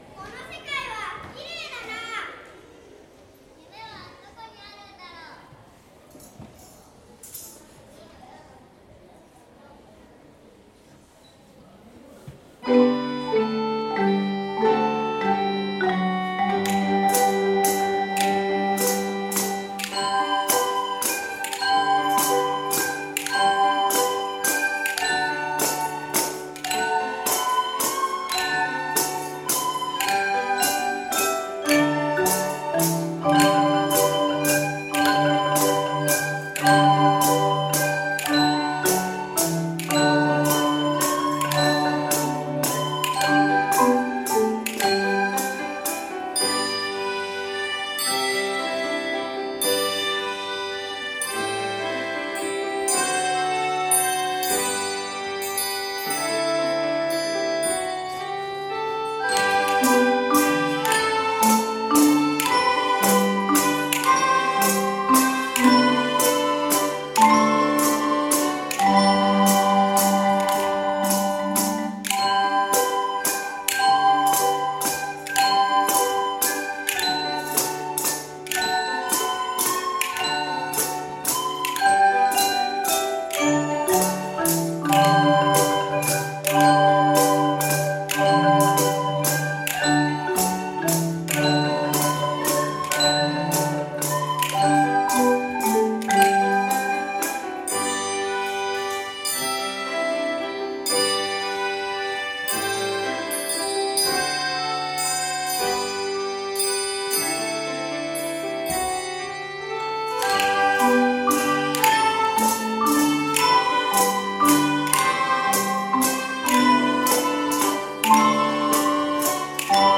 令和５年度　音楽会
合奏 「